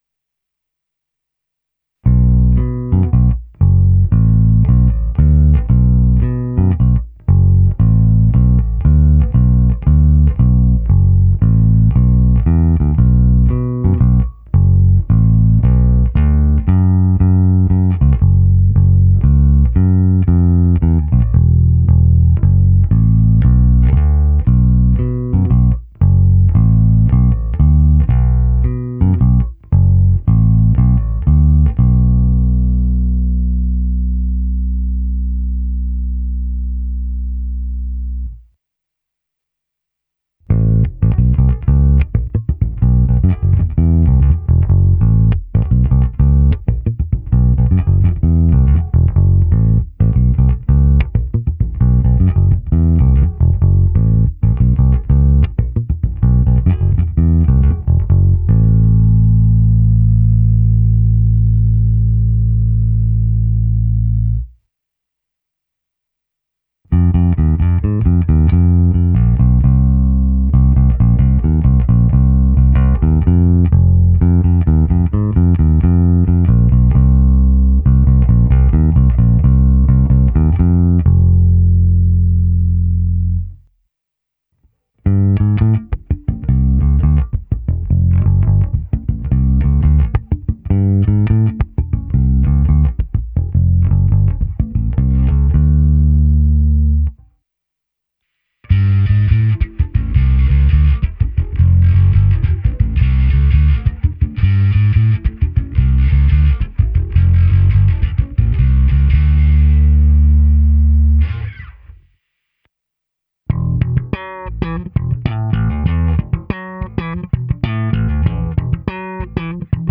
Zvuk je plný, pevný, bohatý středobasový základ doplňuje slušná porce kousavosti.
Ukázka se simulací aparátu prostřednictvím Darkglass Harmonic Boosteru, Microtubes X Ultra a kompresoru TC Electronic SpectraComp. Použito zkreslení, a ač strunami jsou ne úplně na tuto techniku vhodné struny, tak i slap.